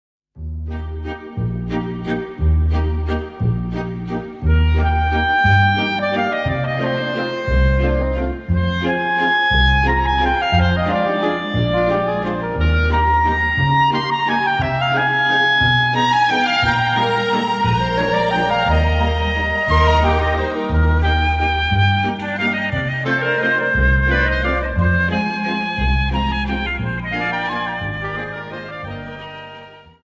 Viennese Waltz 59 Song